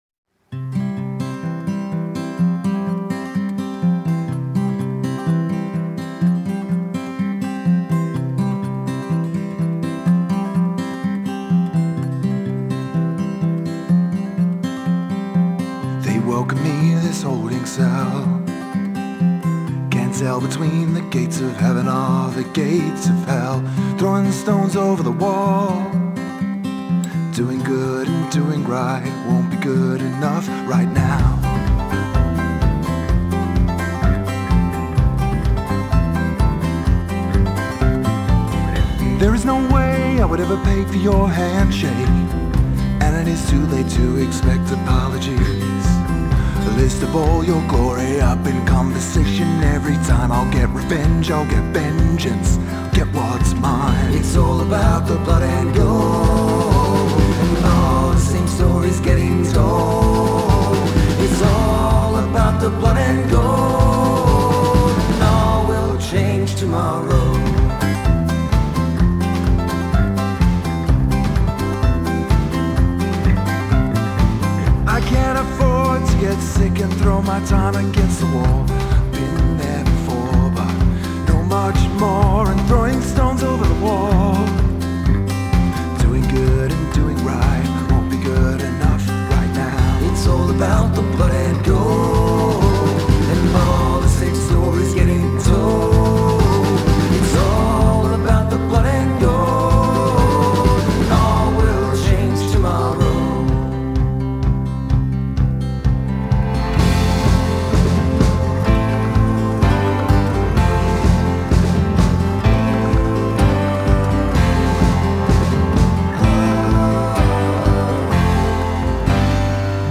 Genre: Folk, Roots, Alternative